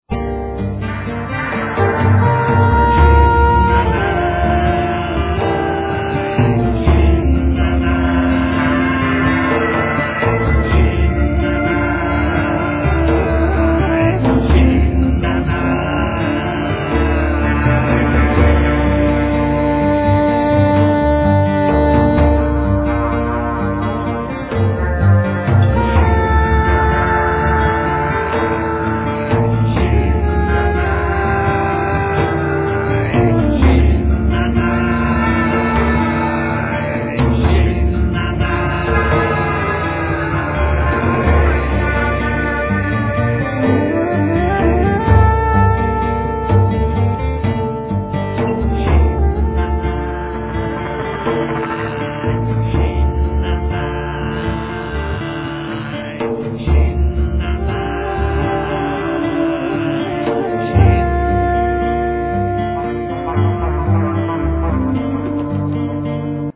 Guitars, Voice, Percussions
Saxophone
Accordion
Viola